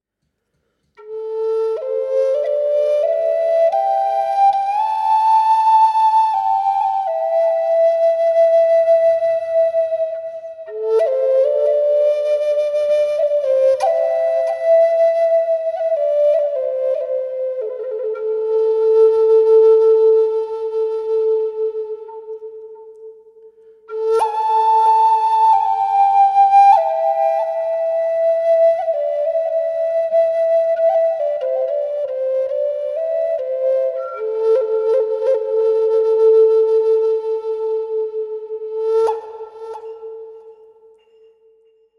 A4 sävellajin Natiivihuilu
Korkeahko ja lempeä sointitaajuus. Viritetty pentatoniseen molli sävelasteikkoon.
• Vire: Pentatoninen molli (440 hz)
Ääninäyte kaiku/reverb efektillä:
A4_440hz_pentatoninenmolli_FX.mp3